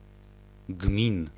Polish[16] gmin
[ɡmʲin̪] 'plebs' See Polish phonology